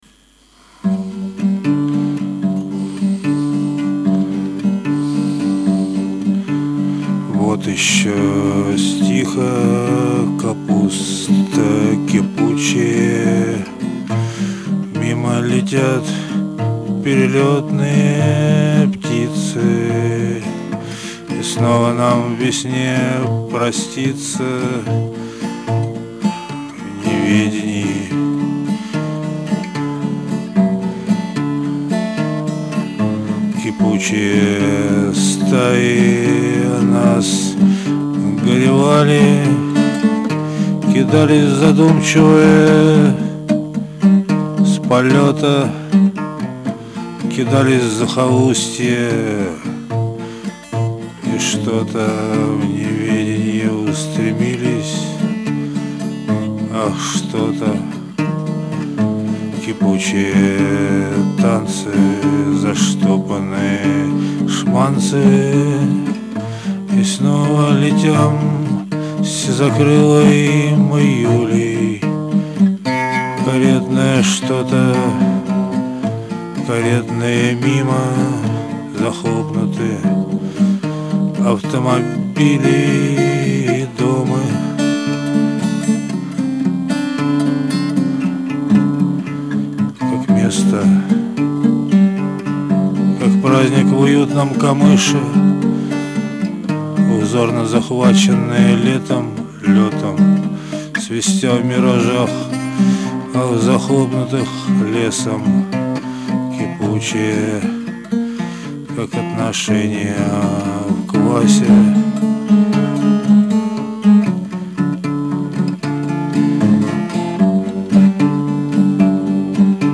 пестня, перепел